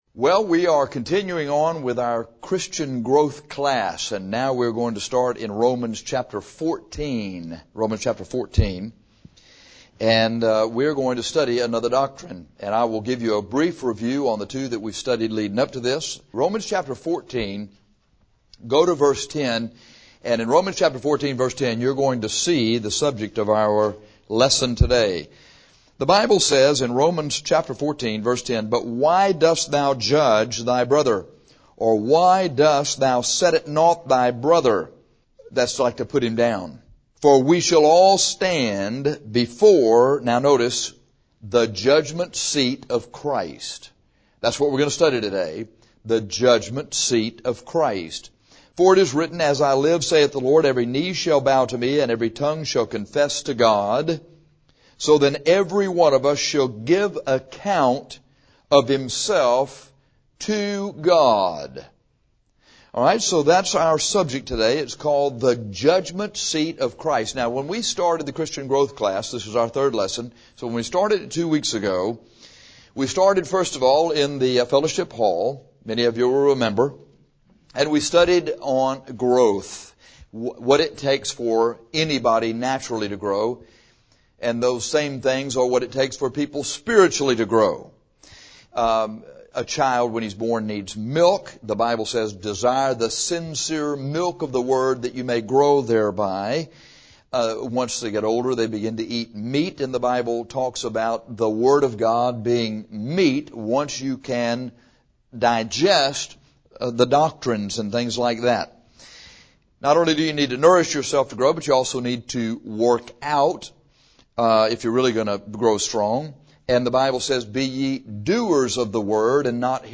Christian Growth III is the third in a series of lessons to help new Christians begin to grow in the Lord. Lesson III is on the judgment seat of Christ.